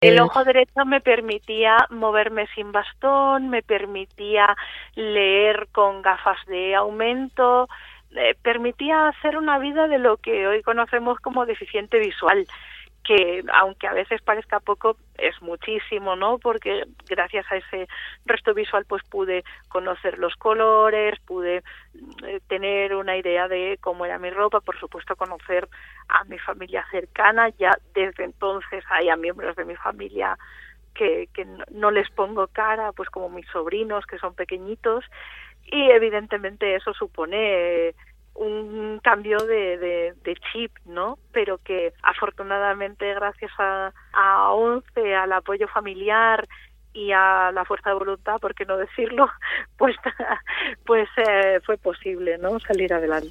con emoción formato MP3 audio(1,02 MB).